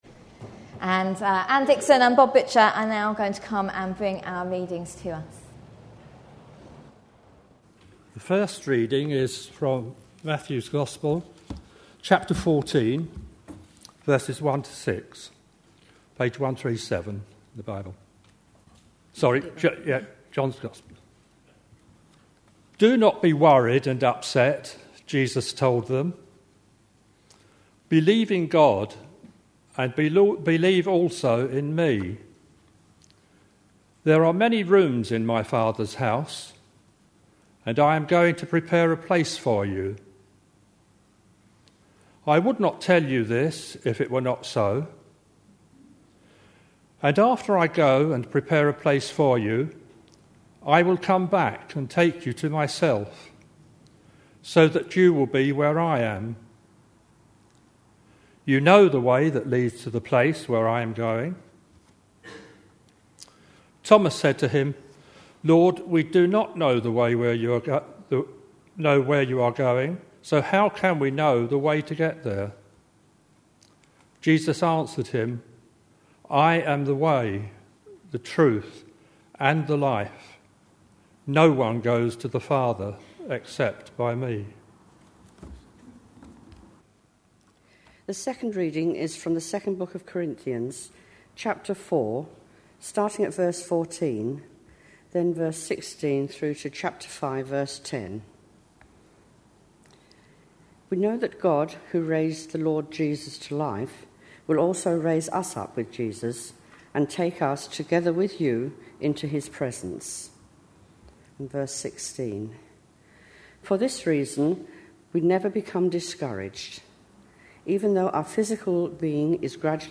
A sermon preached on 10th July, 2011, as part of our God At Work In Our Lives. series.